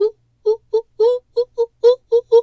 monkey_2_chatter_05.wav